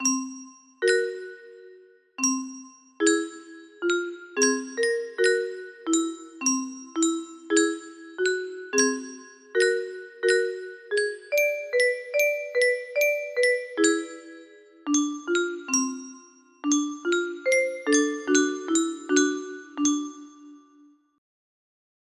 . music box melody